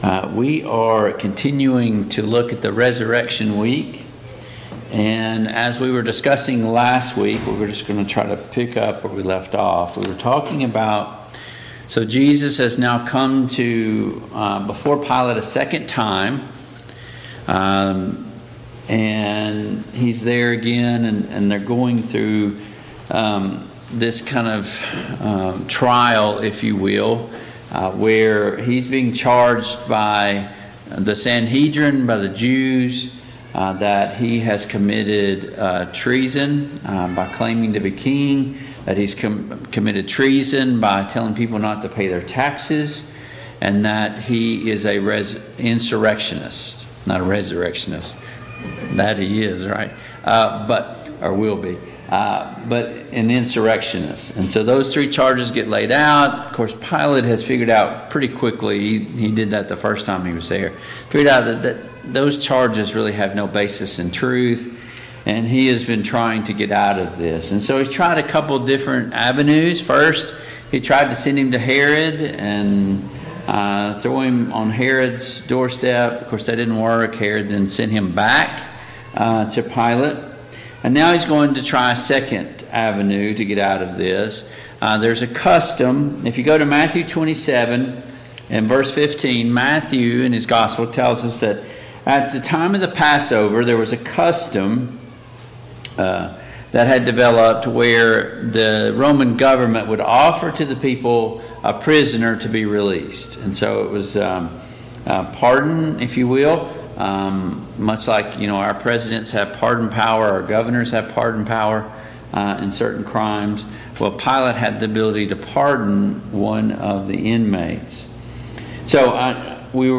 Biblical Timeline of the Jesus’ Resurrection Service Type: Mid-Week Bible Study Download Files Notes « 4.